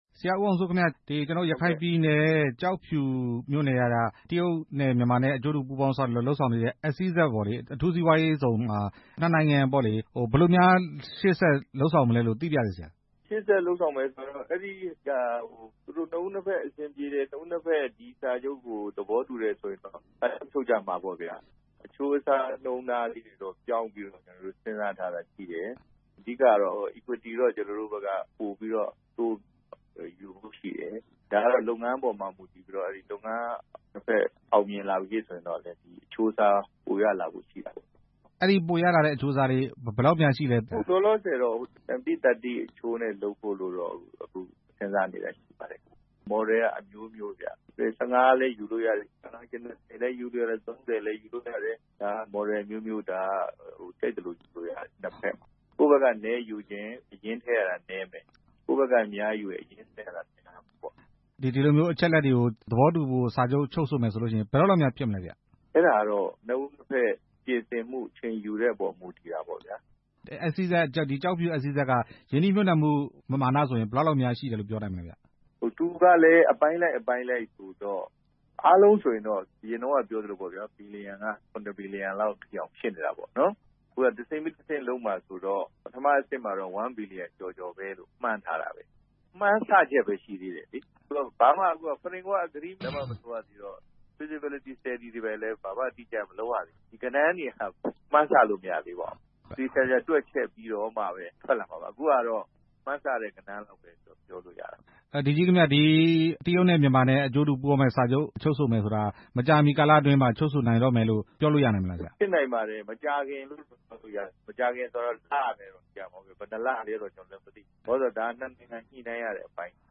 ရခိုင်ပြည်နယ် ကျောက်ဖြူ အထူးစီးပွားရေးဇုန်အကြောင်း မေးမြန်းချက်